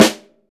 Snare One Shot A Key 325.wav
Royality free snare sample tuned to the A note. Loudest frequency: 1650Hz
snare-one-shot-a-key-325-QIq.mp3